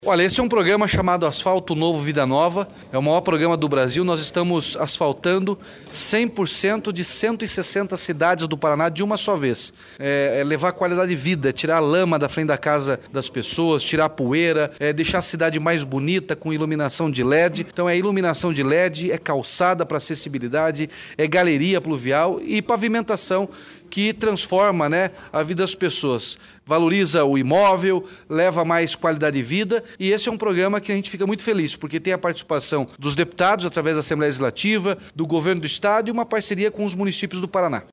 Sonora do governador Ratinho Junior sobre a liberação de R$ 45,3 milhões para 11 municípios por meio do programa Asfalto Novo, Vida Nova